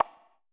cursor-tap.wav